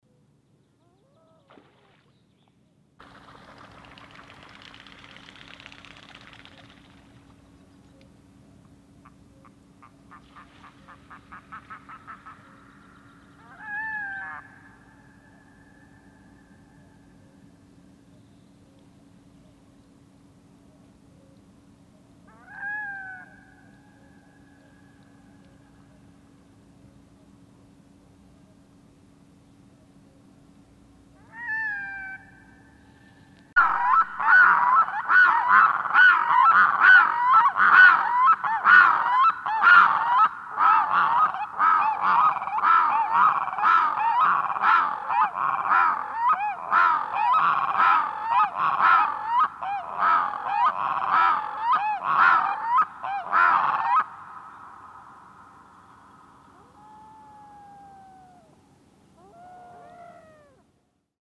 Kaakkuri
Kaakkuri on Suomen kovaäänisin lintu – Kuuntele itse.
Näytteellä olevat äänet ovat aitoja, eikä niitä ole juurikaan editoitu. Vain ensimmäisten sekuntien ajalta on poistettu liiallista mikrofonin aiheuttamaa sihinää.
Äänimaisema alkaa toisen linnun lentoon lähdöllä ja sen jälkeisillä tyypillisillä ka-ka-ka-ka-ka-ka -lentoäänillä.
Sen jälkeen lammelle jäänyt puoliso valittaa yksinäisyyttään  korkeilla ooh-huudoilla.
Tämän jälkeen kuulet parin keskustelevan ”äänekkäästi” päästelemällä yhtäaikaisesti KARR-KORR-KARR-KORR –huutoja.
Lopuksi kuuluu vielä pari kertaa vaimeita uuu-huutoja, joita linnut päästävät silloin tällöin oleskellessaan yhdessä lammella.